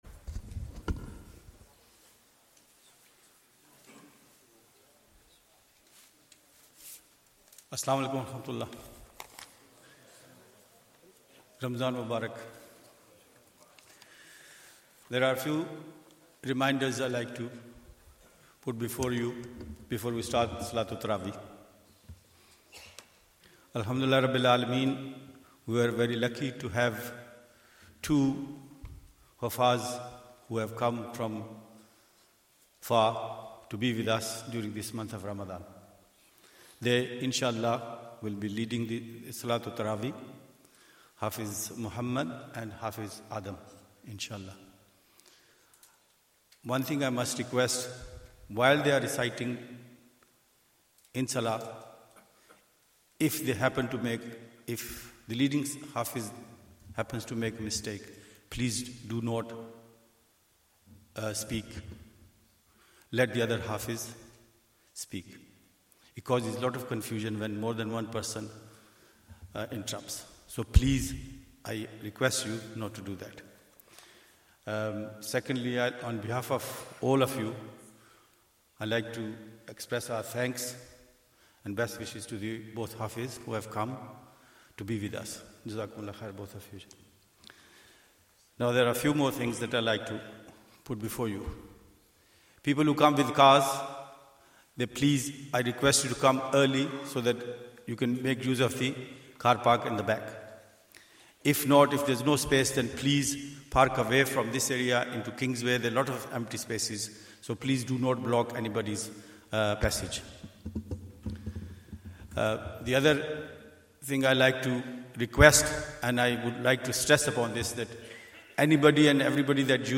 Day 1 - Taraweeh 1444 - Recital